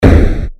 Hit Skill9.wav